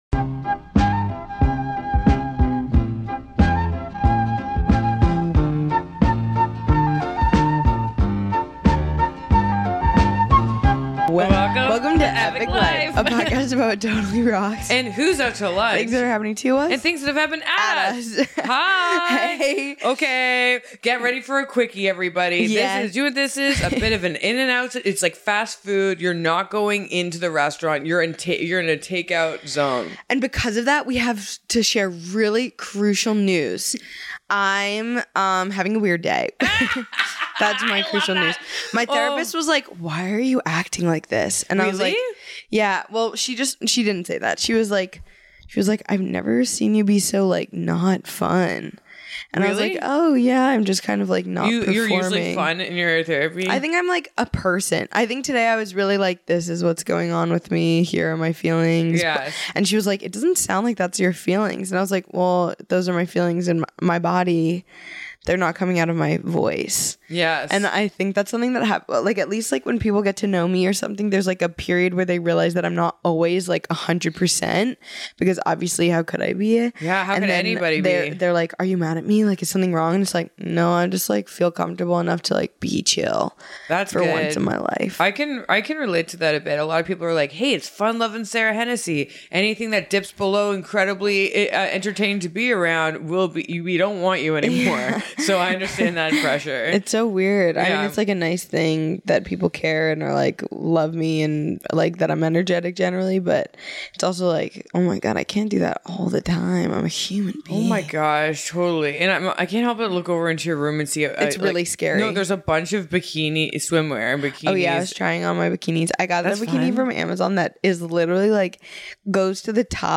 Hey, well, it's a quicky for ya, and the girlies are on their individual couches just letting it spill!